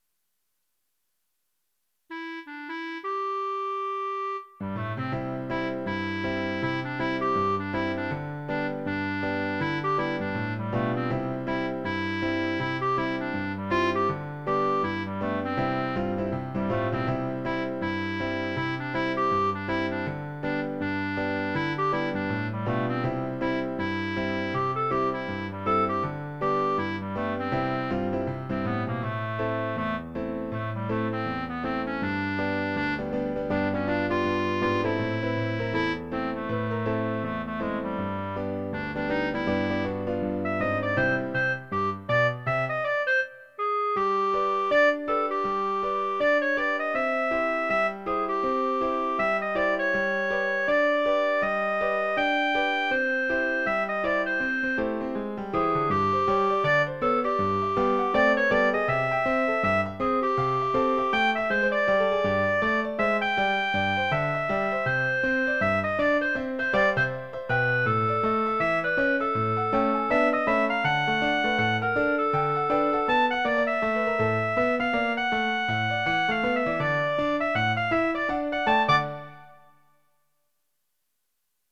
Duet, Piano, Clarinet